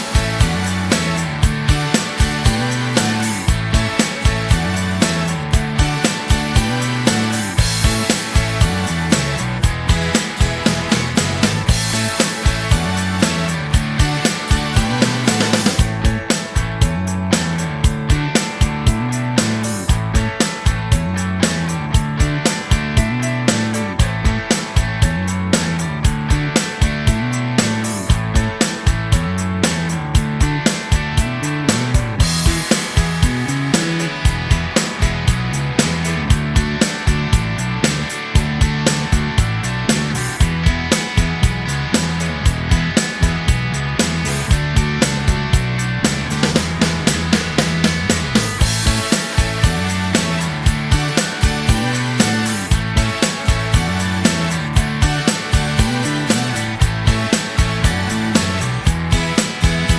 Minus Main Guitar For Guitarists 4:16 Buy £1.50